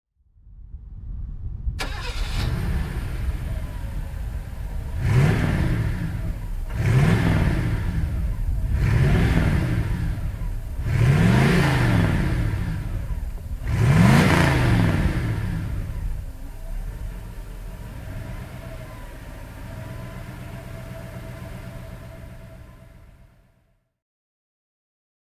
Mercedes-Benz 500 SEC (1984) - Starten und Leerlauf